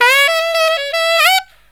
63SAXMD 04-L.wav